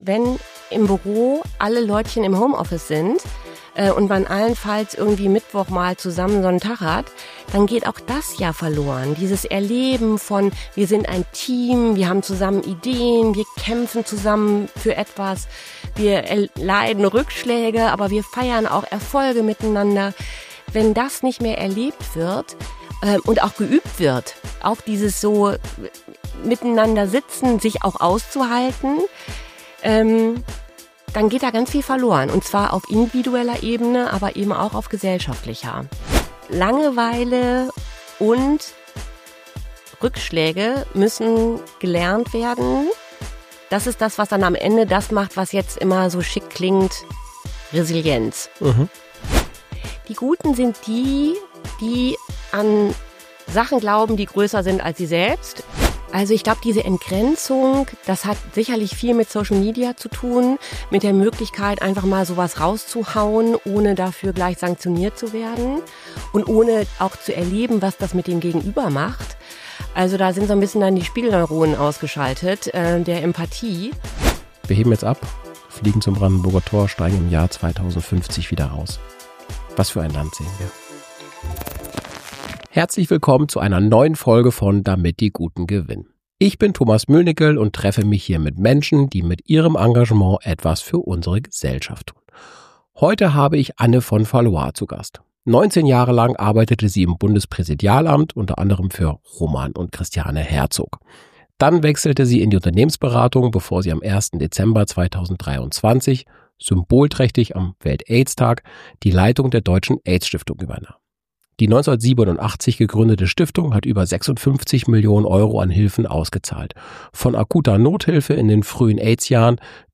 Ein ehrliches Gespräch über Haltung, Menschlichkeit – und darüber, wie wichtig es ist, nicht nur tolerant zu sein, sondern aktiv für eine offene Gesellschaft einzustehen.